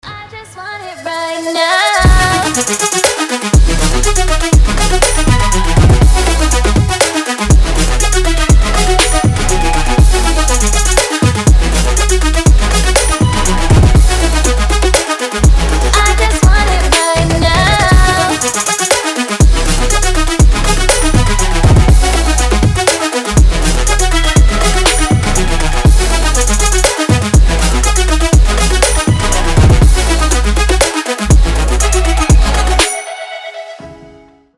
• Качество: 320, Stereo
громкие
EDM
красивый женский голос
future bass
Стиль: trap / future bass